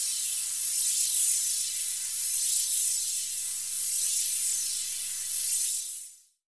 Index of /90_sSampleCDs/Chillout (ambient1&2)/09 Flutterings (pad)
Amb1n2_m_flutter.wav